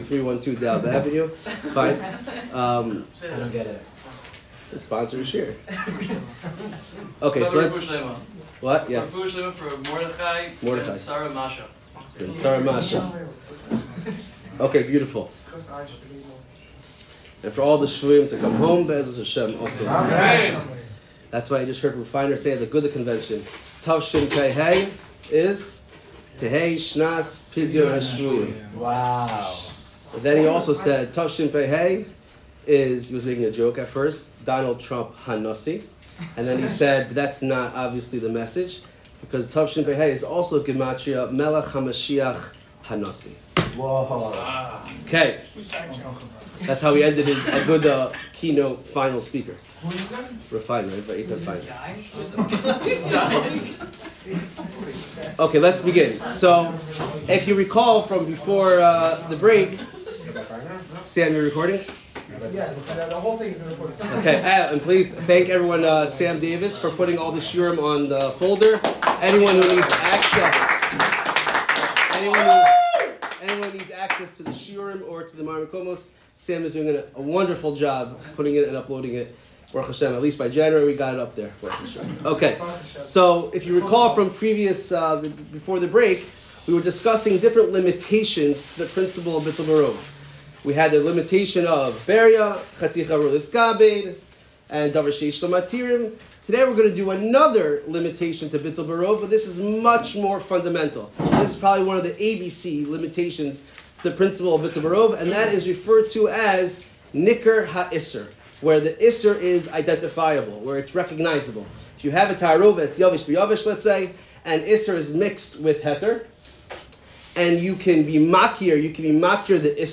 Shiur 15 - ניכר